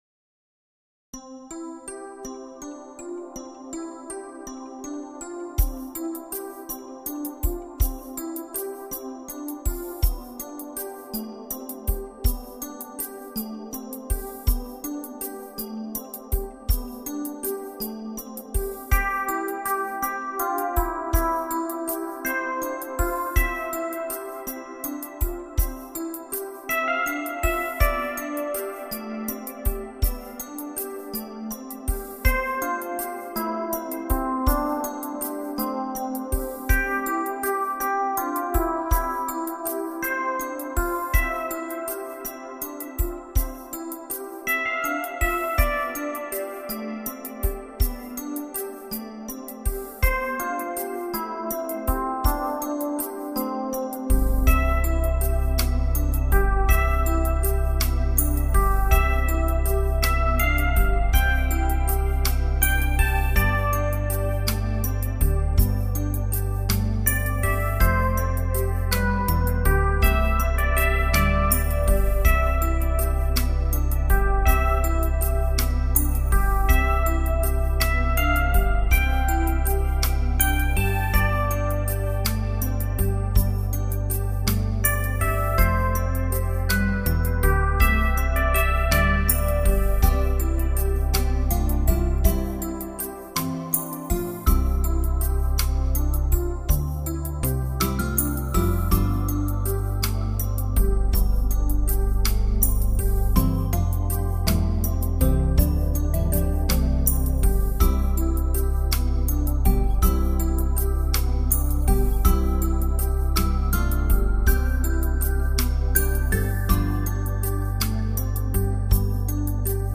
音乐流派: New Age